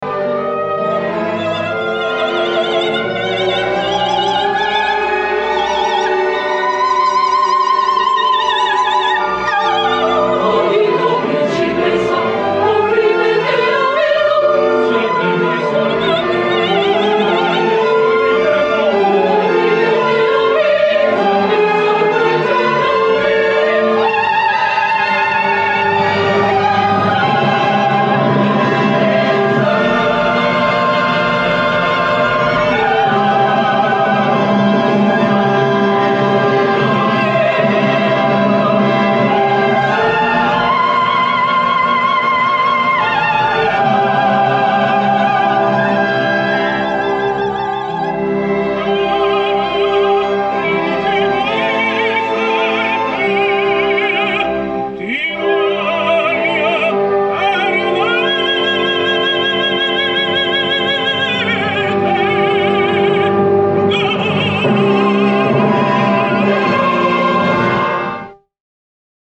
Not a great career, but still a surprisingly good one, for a singer with such a bad wobble already in young years, and such screamed and pushed high notes.